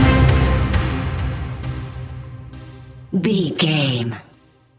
Ionian/Major
C♭
techno
trance
synths
synthwave
instrumentals